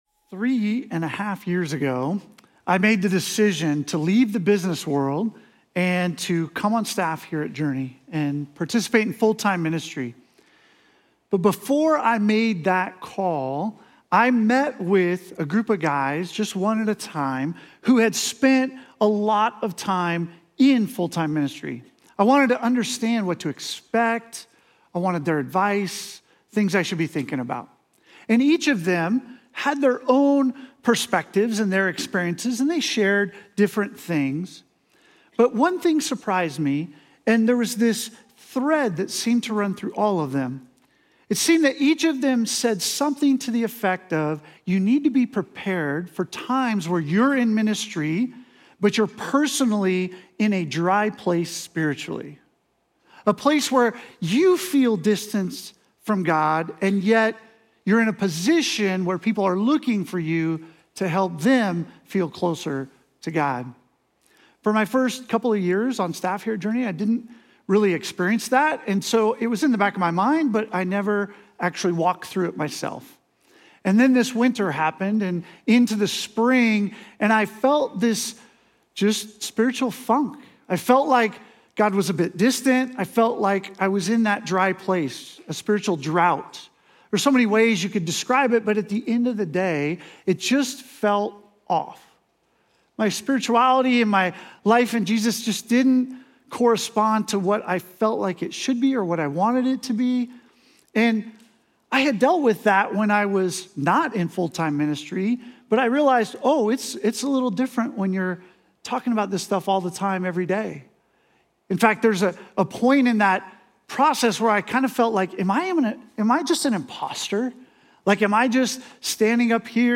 Journey Church Bozeman Sermons Summer In The Psalms: Spiritual Dryness Aug 04 2025 | 00:37:05 1x 00:00 / 00:37:05 Subscribe Share Apple Podcasts Overcast RSS Feed Share Link Embed